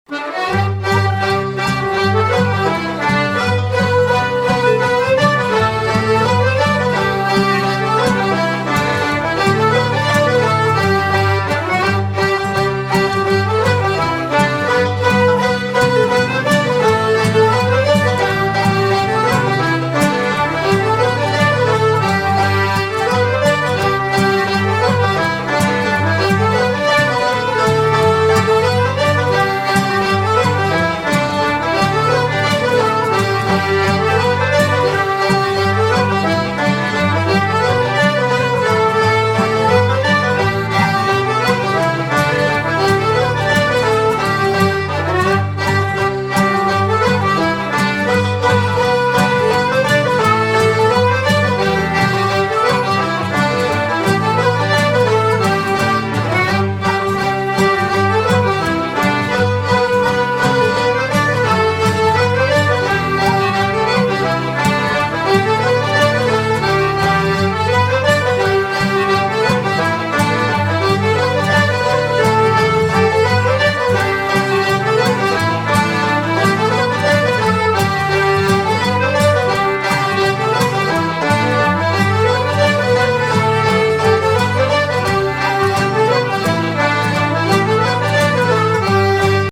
Lucy Farr’s Barn Dance set
our session players